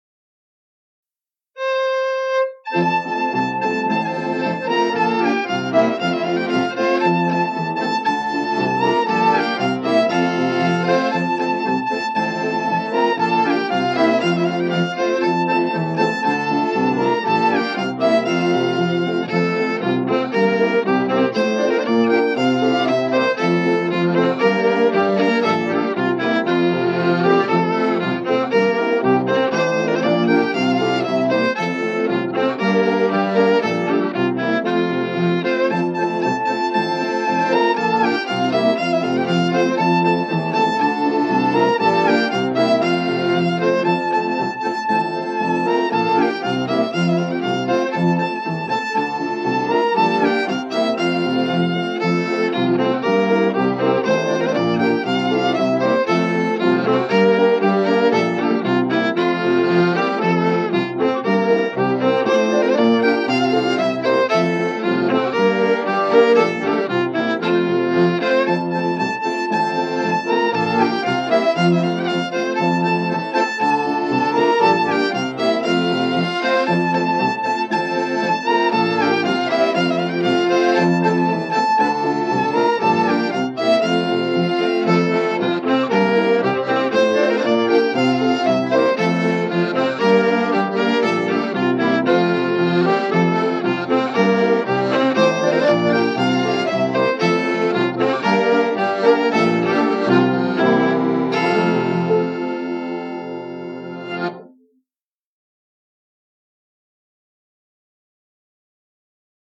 Folk dance
fiddle
accordion
piano. I was roped in because I had a reel-to-reel tape deck.
Formation 3 couple longways Folk dance Music Here are the Kenton Ramblers: Miss Sayer's Allemande (3x32 bars) (file size 2.5 MB) A score is available at abcnotation .